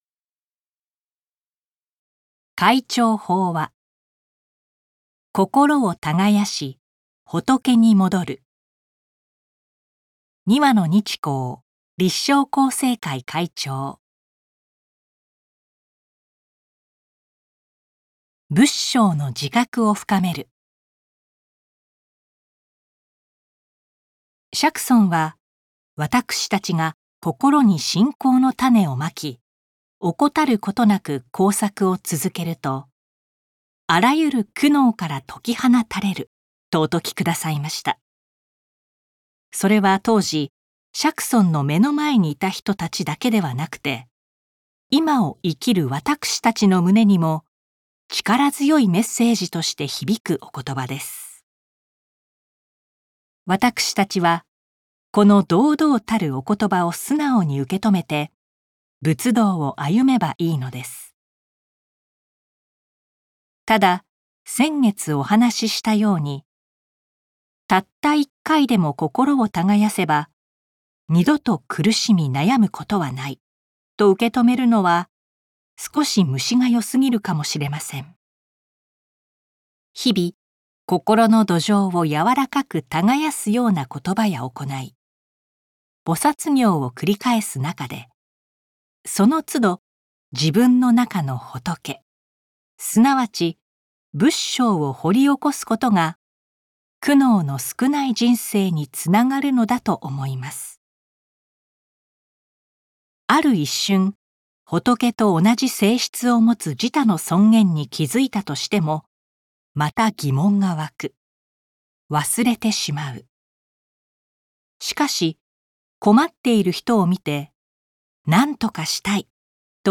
朗読MP3 podcast